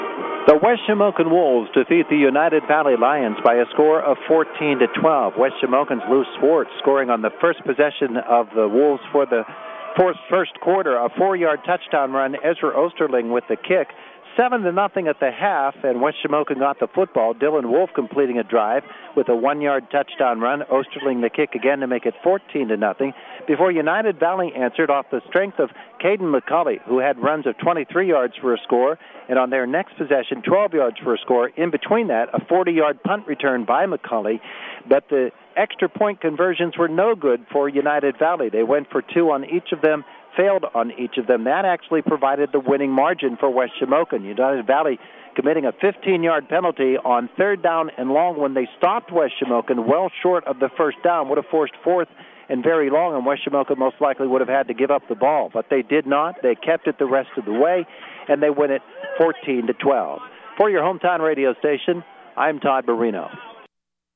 recap of the West Shamokin win on U92.5 FM
united-valley-vs-w-shamokin-recap.mp3